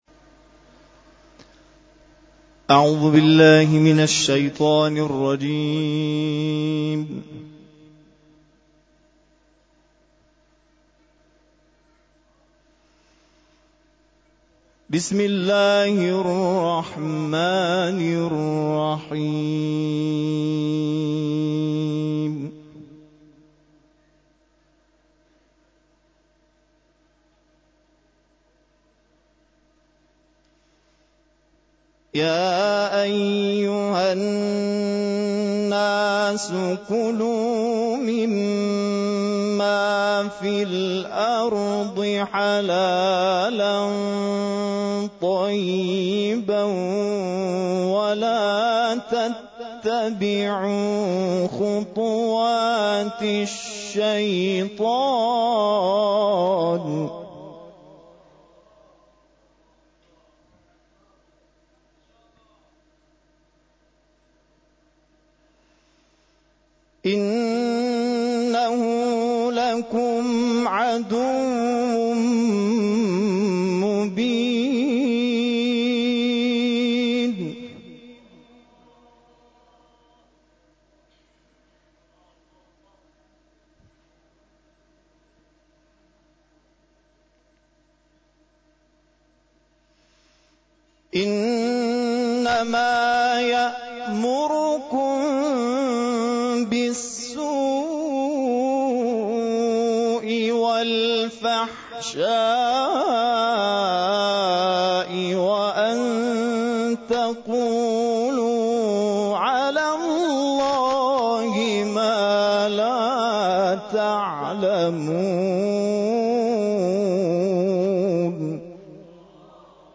تلاوت‌های قاریان برای اعزام به مسابقات بین‌المللی + عکس و صوت
گروه فعالیت‌های قرآنی: 9 نفر از قاریان کشورمان که طی مسابقات سراسری قرآن امسال و سال گذشته حائز رتبه‌های برتر شدند شب گذشته، 16 بهمن در مرحله ارزیابی و انتخاب قاریان برای اعزام به مسابقات بین‌المللی ایران و دیگر کشورها در حضور داوران اجرای تلاوت داشتند.